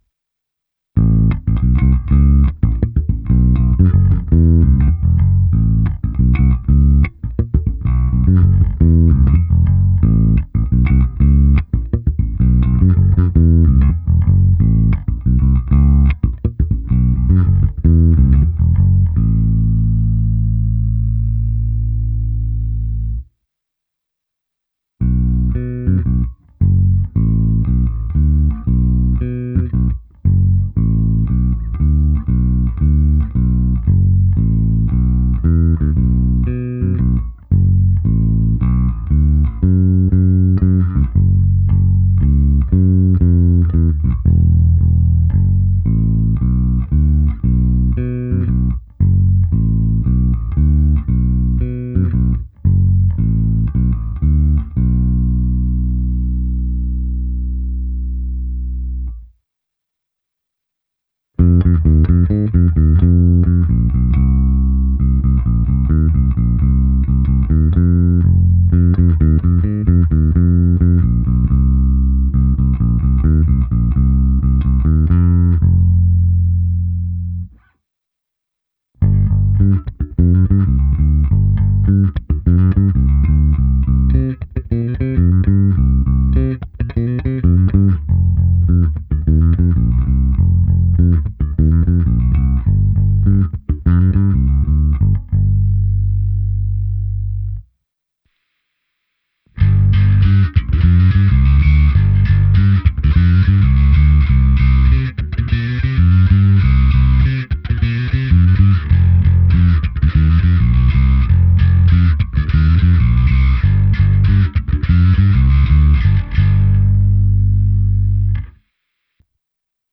Abych simuloval, jak hraje baskytara přes aparát, protáhl jsem ji preampem Darkglass Harmonic Booster, kompresorem TC Electronic SpectraComp a preampem se simulací aparátu a se zkreslením Darkglass Microtubes X Ultra. Hráno na oba snímače sériově, v nahrávce jsem použil i zkreslení a slap.
Ukázka se simulací aparátu